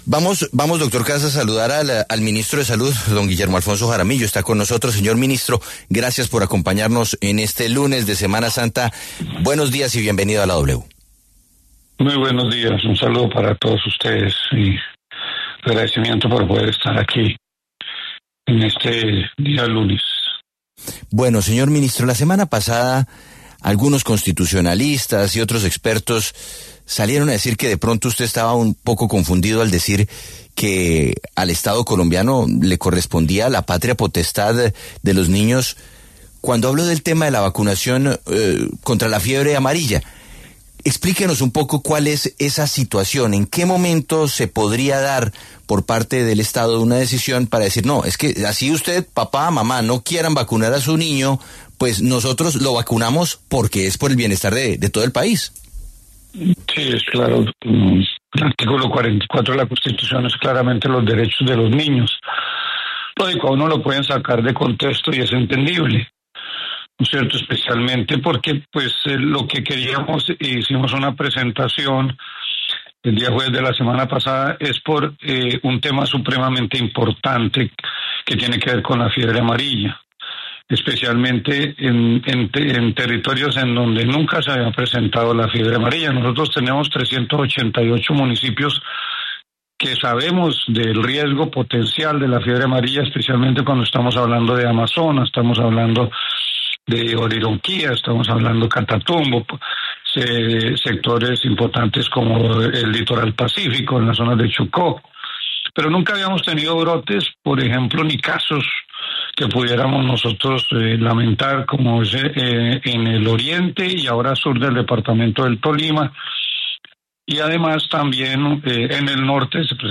El ministro de Salud, Guillermo Jaramillo, explicó en La W que el Estado puede intervenir en situaciones donde los padres se niegan a vacunar a sus hijos, especialmente ante riesgos como la fiebre amarilla.